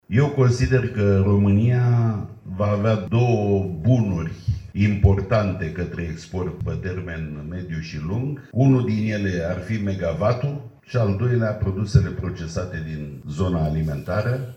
Conferința SMART ENERGY 2025 organizată azi la Constanța a adus în prim plan energia și, mai precis, viitorul energiei inteligente între inovație, digitalizare și eficiență.